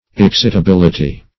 Excitability \Ex*cit"a*bil"i*ty\, n. [Cf. F. excitabilit['e].]